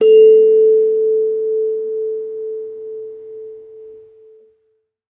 kalimba1_circleskin-A3-pp.wav